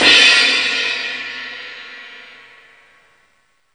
CRASH08   -R.wav